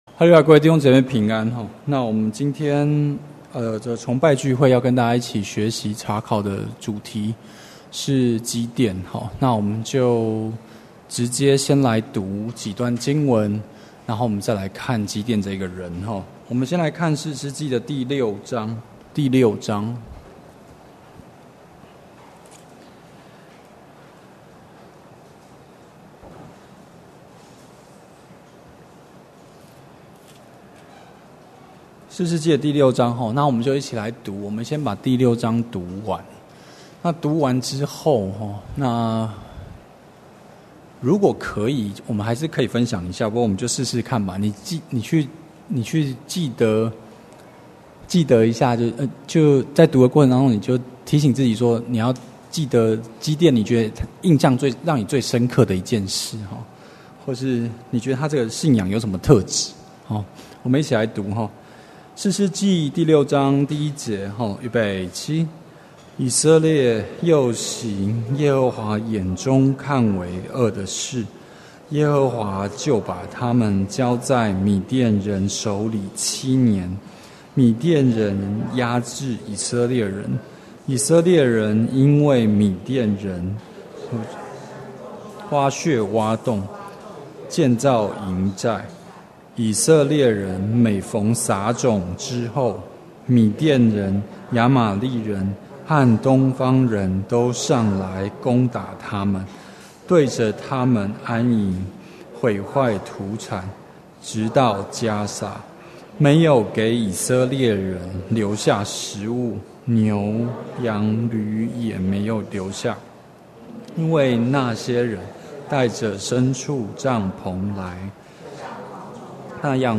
2017年9月份講道錄音已全部上線